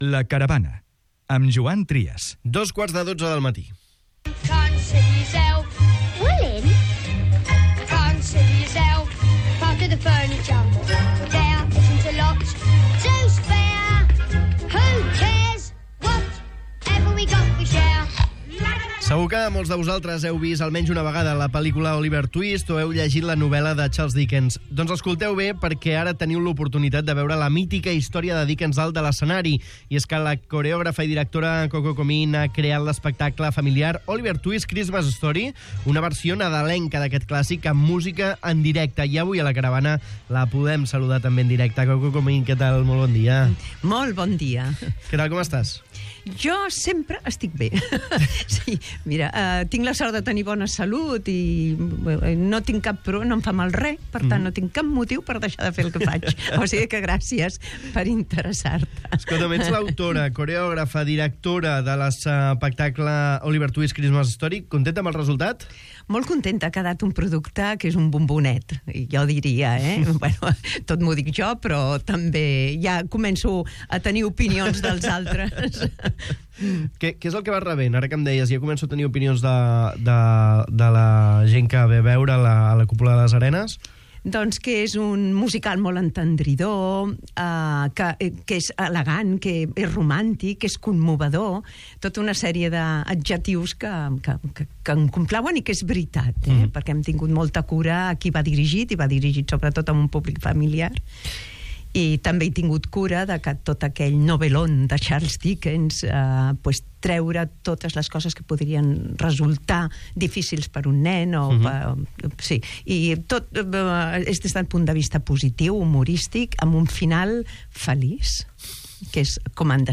En una entrevista a 'La Caravana' de Ràdio Estel